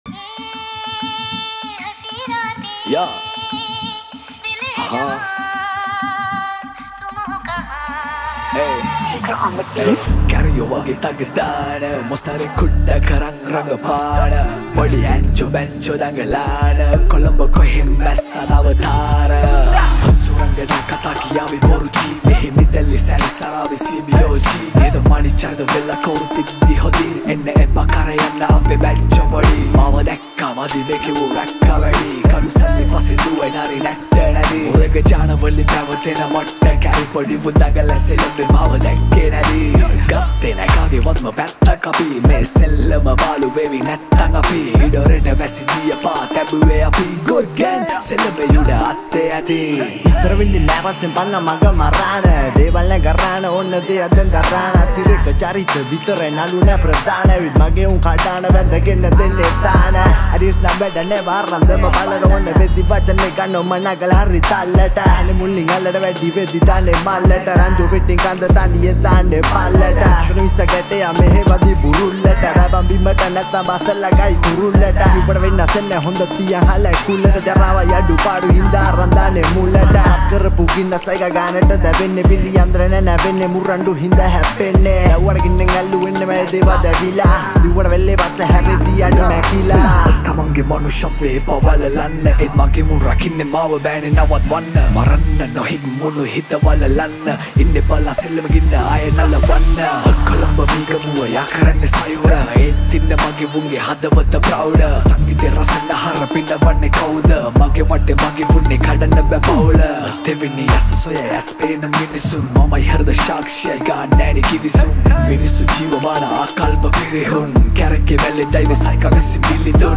Releted Files Of Sinhala New Rap Mp3 Songs